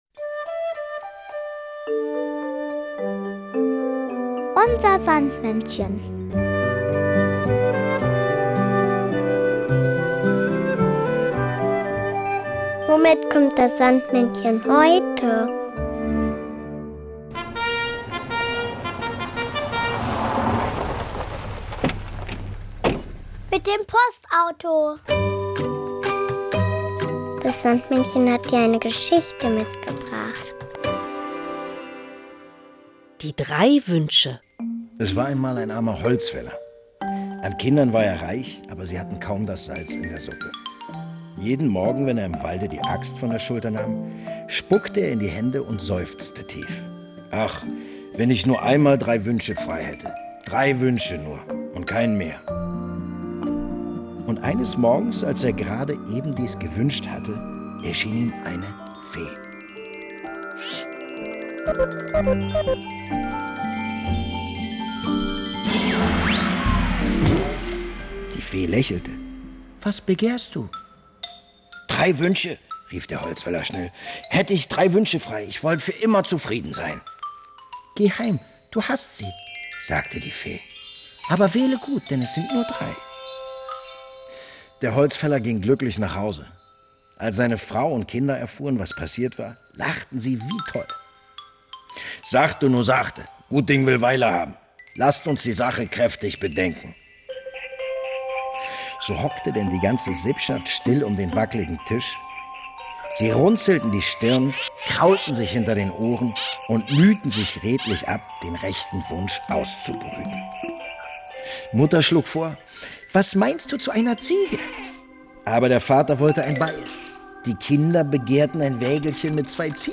Märchen: Die drei Wünsche